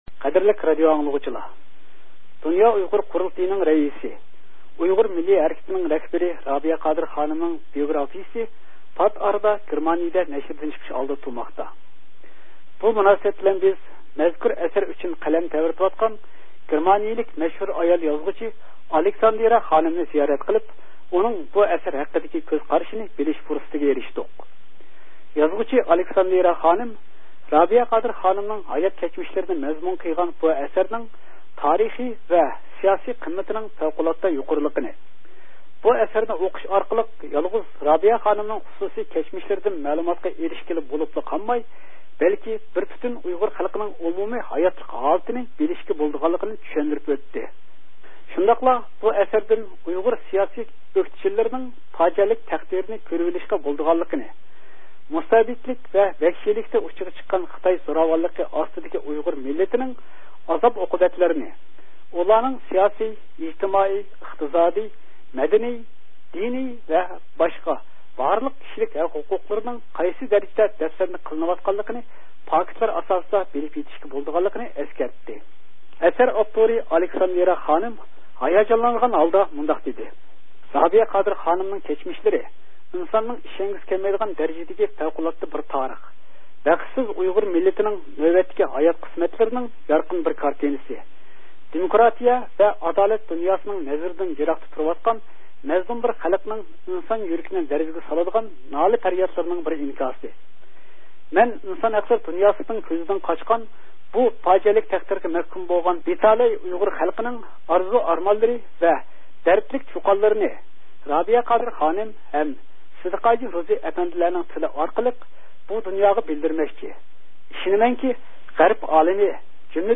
سۆھبەت ئېلىپ باردى